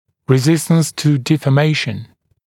[rɪ’zɪstəns tə ˌdiːfɔː’meɪʃn][ри’зистэнс ту ˌди:фо:’мэйшн]устойчивость к деформации